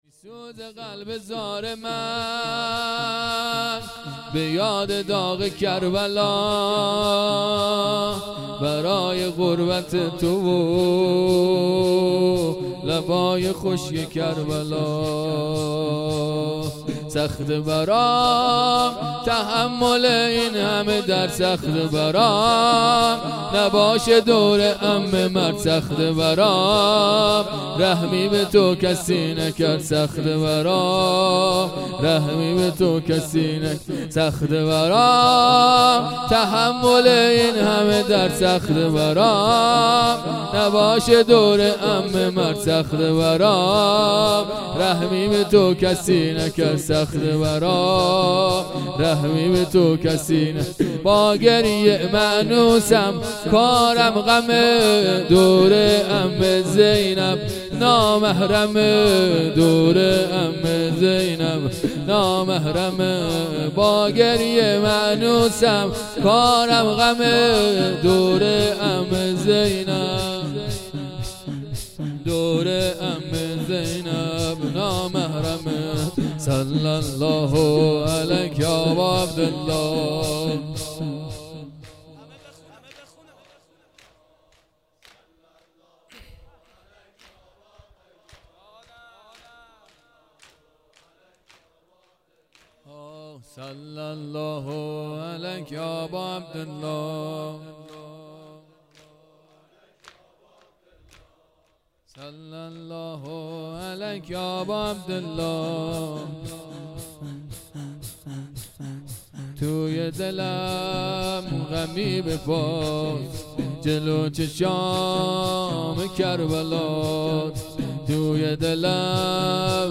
هیئت أین الفاطمیون